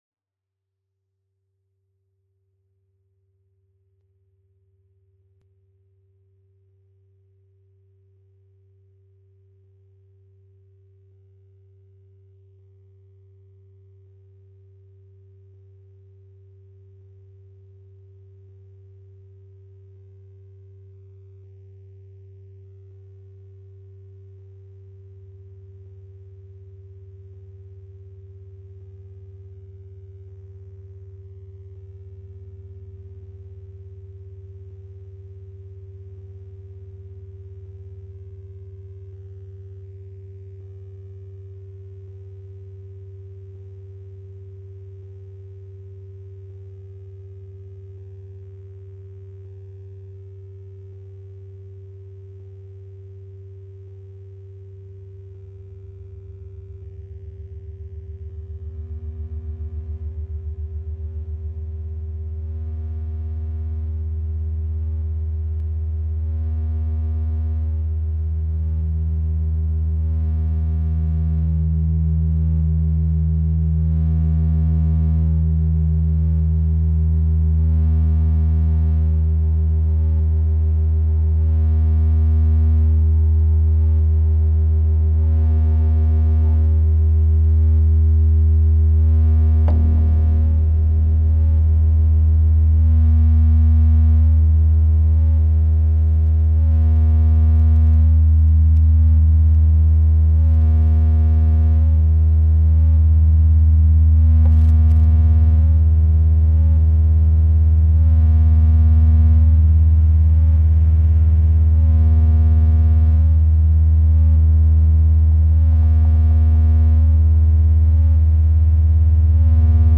02 | 03 | 04 | closed circuit recordings /// 99’